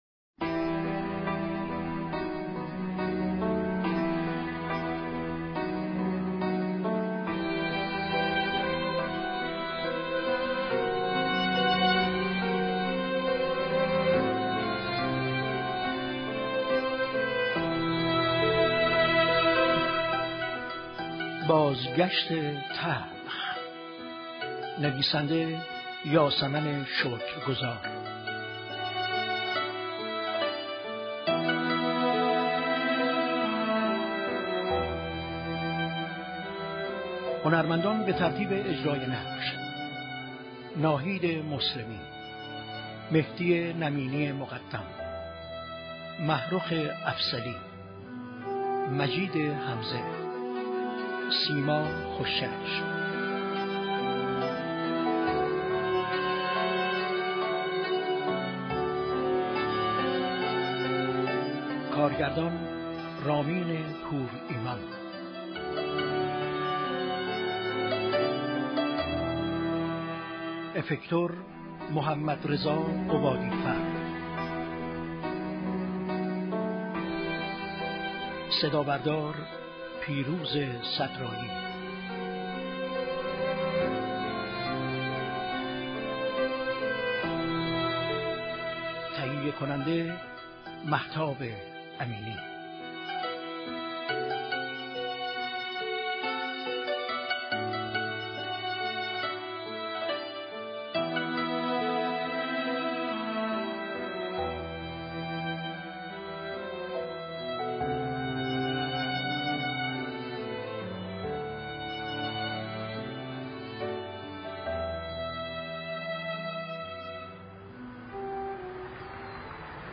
هفتم اسفند ماه ، یك نمایش رادیویی به مناسبت بزرگداشت